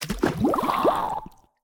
placeinwater.ogg